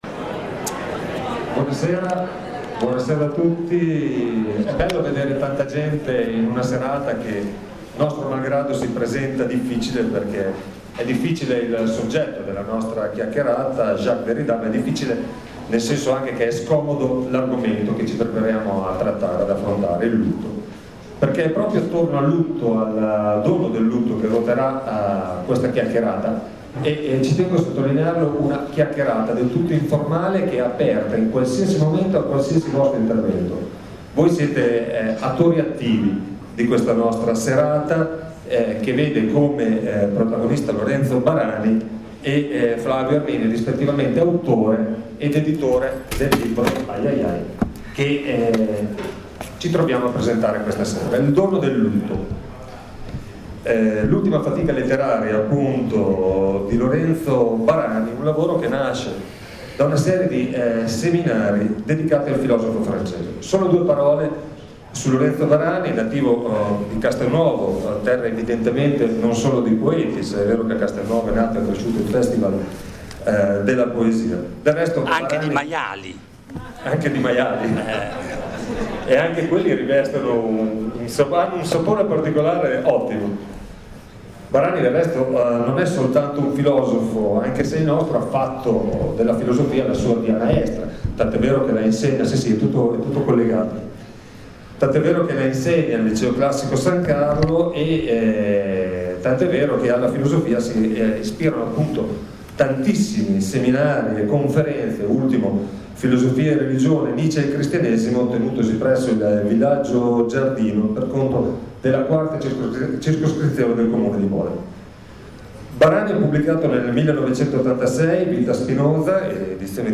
Presentazione del libro